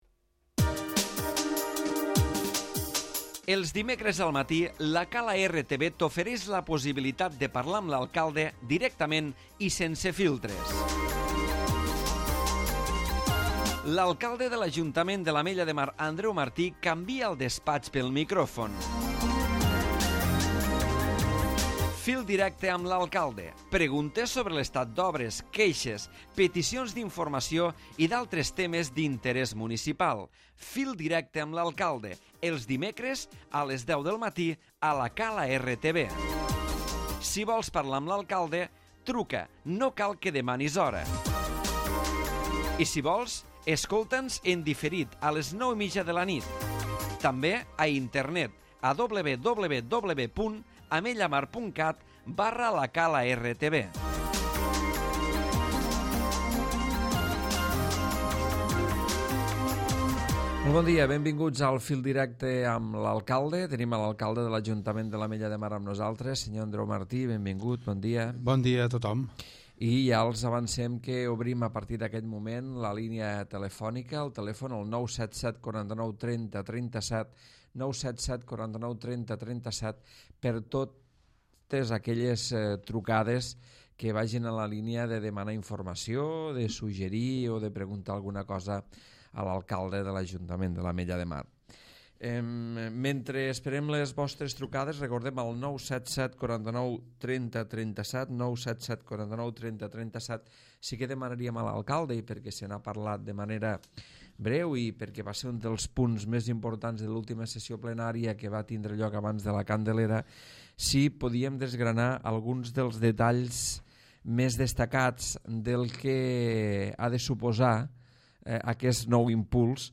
Andreu Martí, alcalde de l'Ajuntament de l'Ametlla de Mar ha participat com de costum al Fil Directe, atenent a les trucades dels ciutadans i desgranant l'actualitat de la gestió municipal.